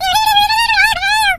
squeak_start_vo_04.ogg